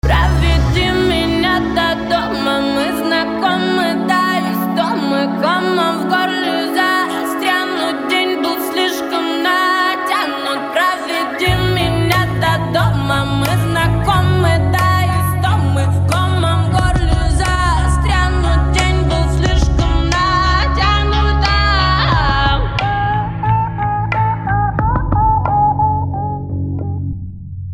• Качество: 192, Stereo
поп
женский вокал
красивый женский голос